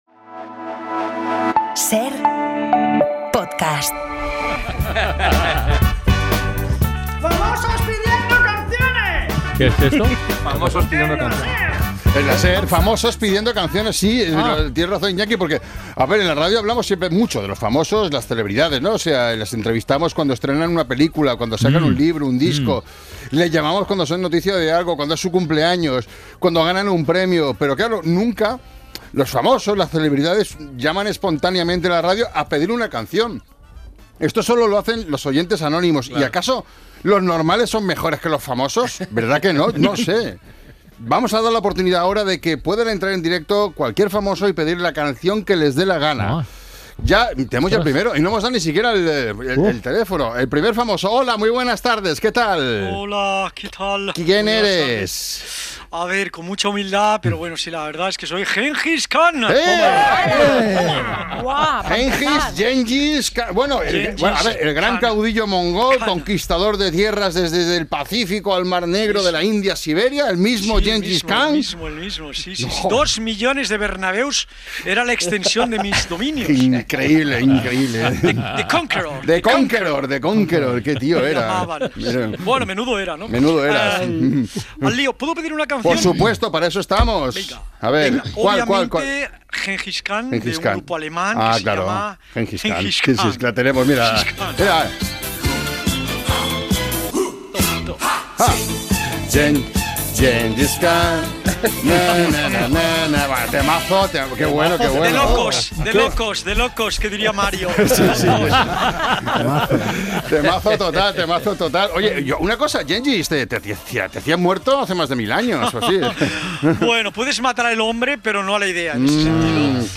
Habla la astronauta Christina Koch en esta sección dedicada a los famosos para que pidan canciones. También nos llama Spiderman para quejarse.